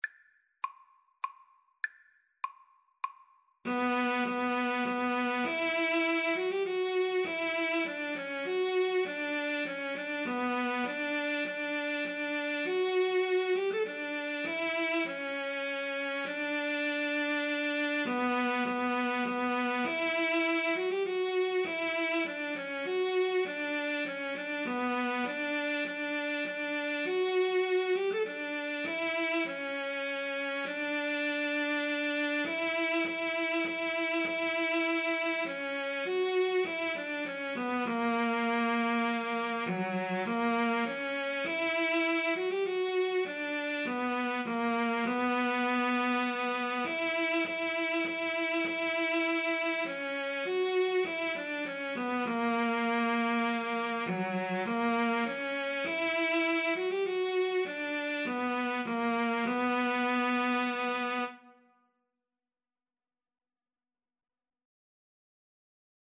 3/4 (View more 3/4 Music)
Classical (View more Classical Cello Duet Music)